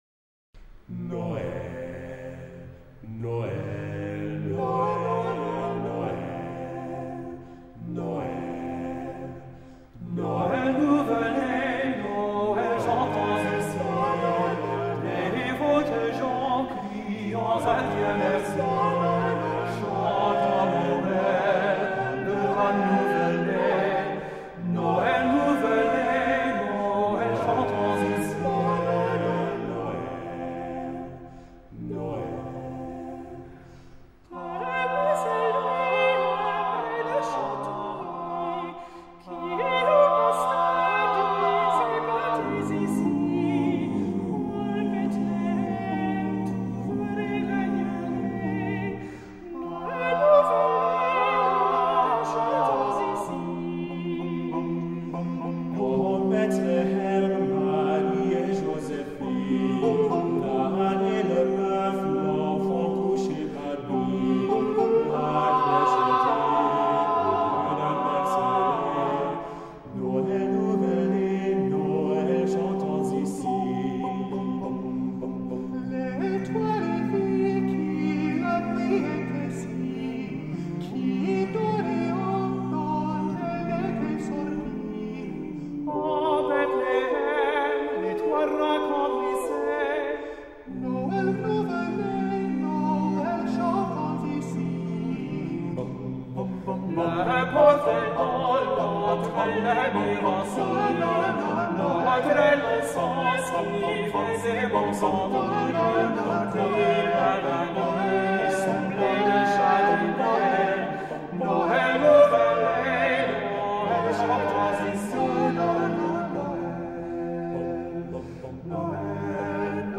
Chant traditionnel français
par The King's Singers